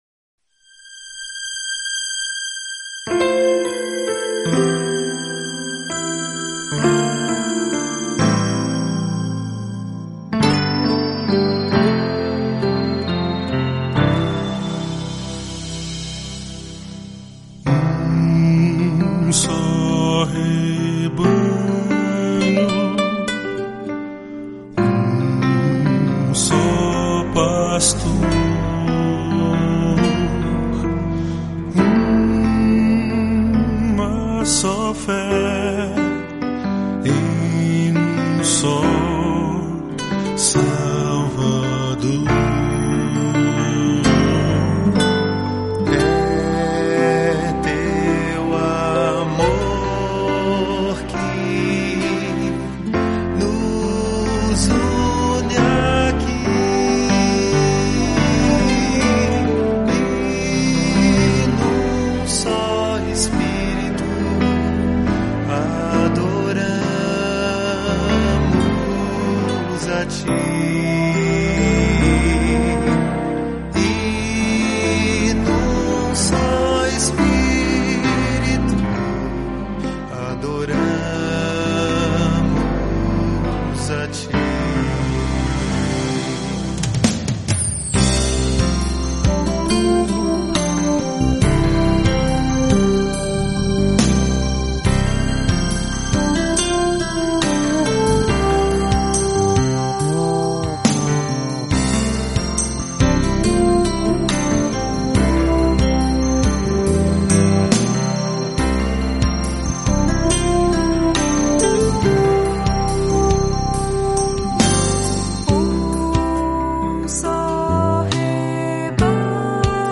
Ocorreu, no entanto, que nesta última semana estive, agora na Rádio Zé FM, para participar do programa Mensagem de Paz, das Igrejas Presbiterianas de Americana.